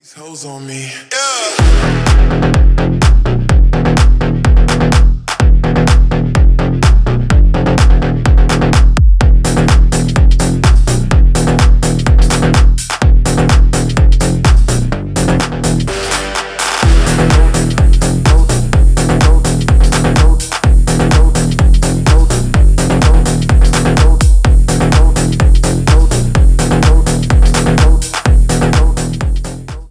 Электроника
без слов
клубные